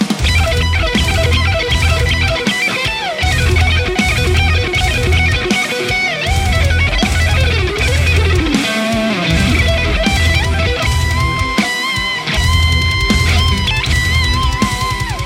Lead Mix
RAW AUDIO CLIPS ONLY, NO POST-PROCESSING EFFECTS